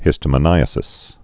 (hĭstə-mə-nīə-sĭs)